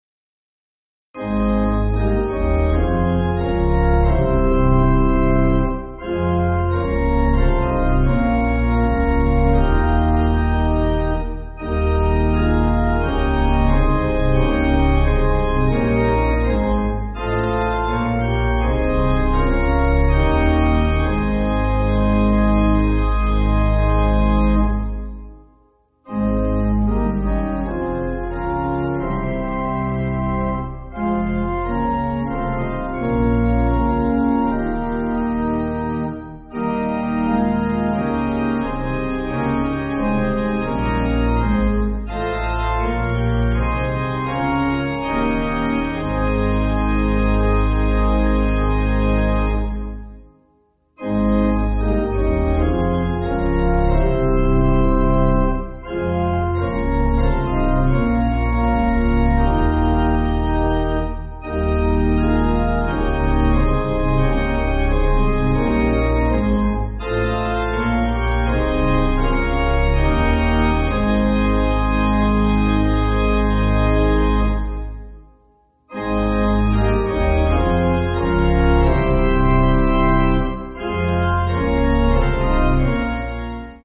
(CM)   4/Ab